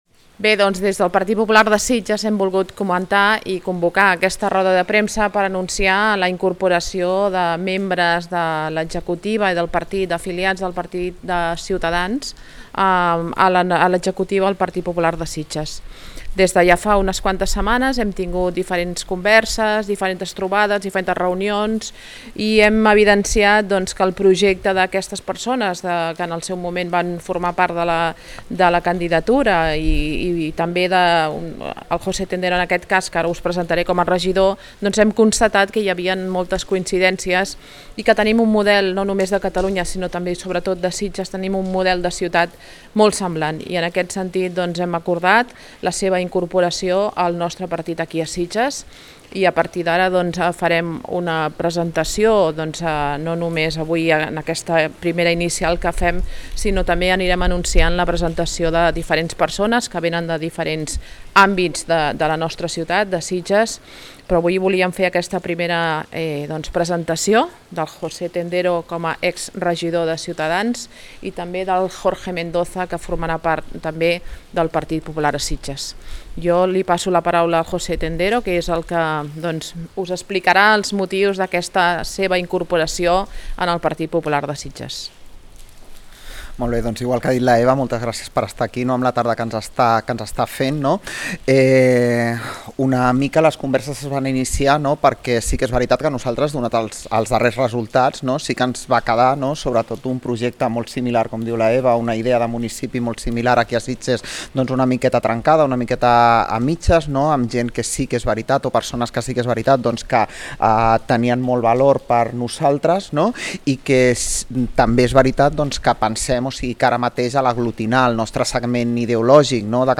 Escolteu la roda de premsa